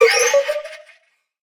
Minecraft Version Minecraft Version snapshot Latest Release | Latest Snapshot snapshot / assets / minecraft / sounds / mob / allay / item_given3.ogg Compare With Compare With Latest Release | Latest Snapshot
item_given3.ogg